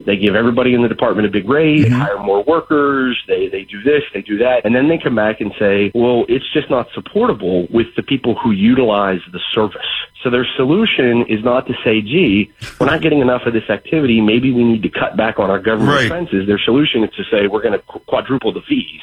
As the result of legislation passed during the Maryland General Assembly, hundreds of new and increased fees have been enacted, affecting state residents. House Minority Leader Jason Buckel spoke recently to reporters about fees, saying they rarely generate the revenue they were intended for…